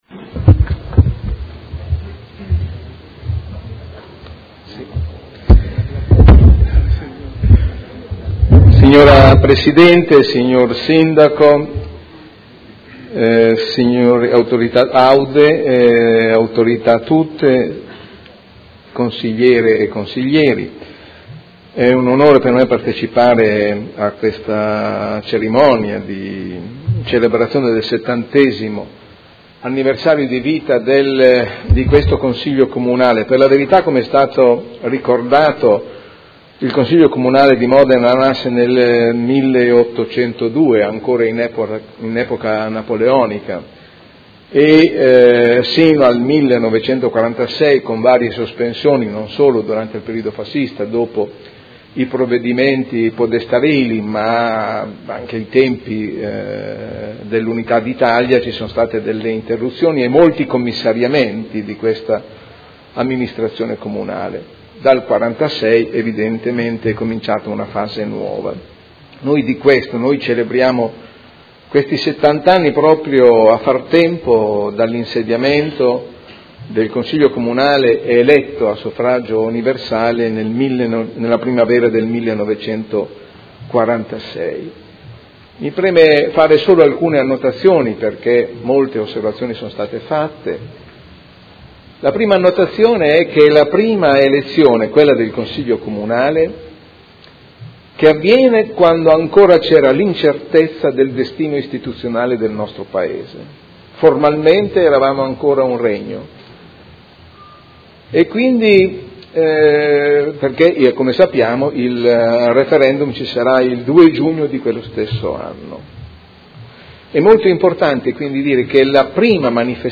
Seduta del 20/04/2016. Dibattito su Celebrazione del 70° dall'insediamento del primo Consiglio Comunale di Modena dopo il periodo fascista